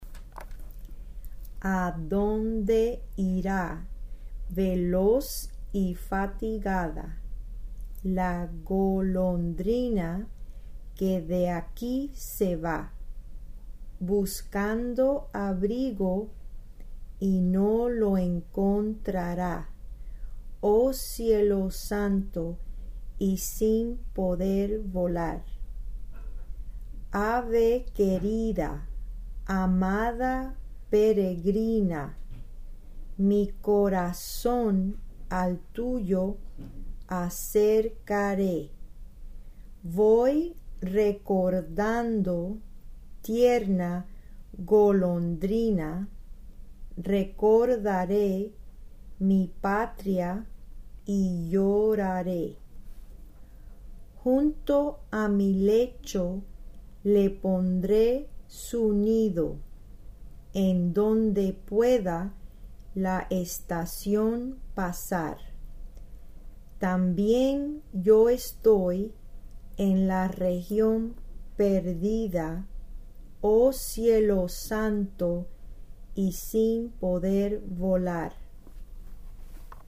Spanish lyrics read